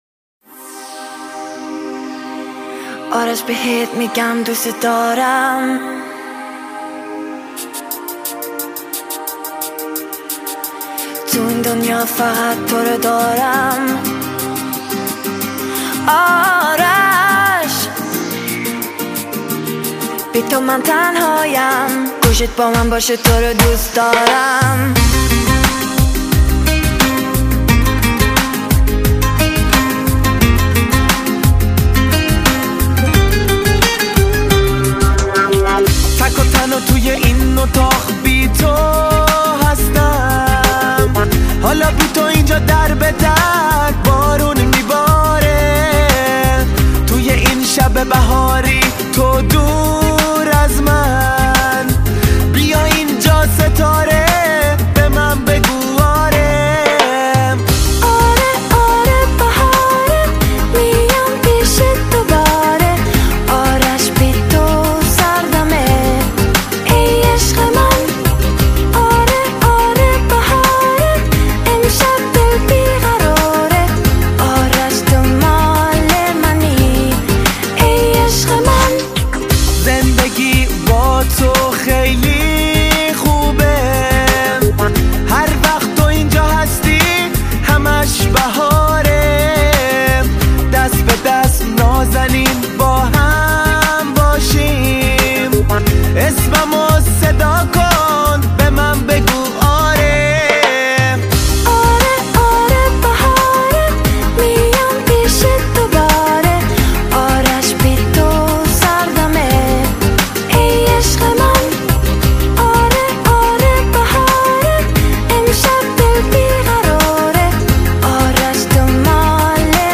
唱片类型：汽车音乐
土耳其、南非西班牙、俄罗斯、等时尚动感至纯情歌，